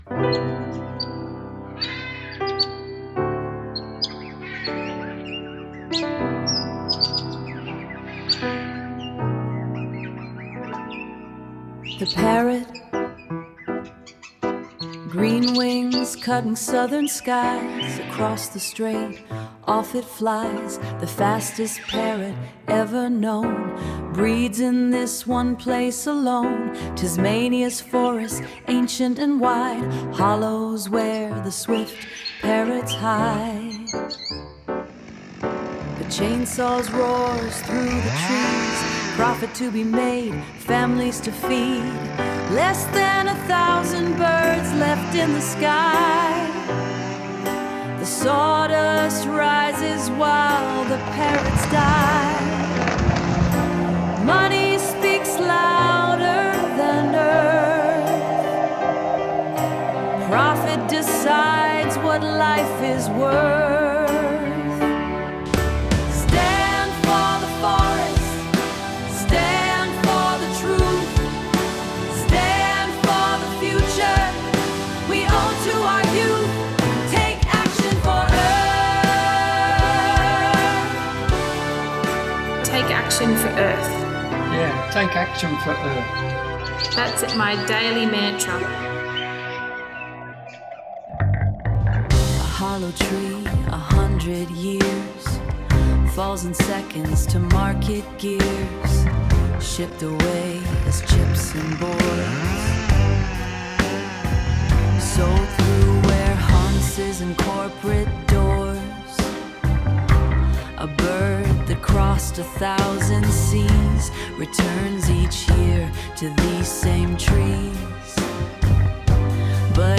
The song is both a lament and a call to courage.